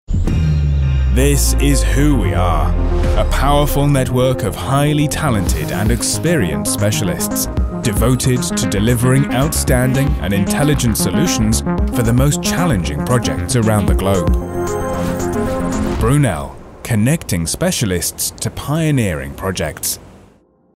English (British)
Corporate Videos
Custom-built home studio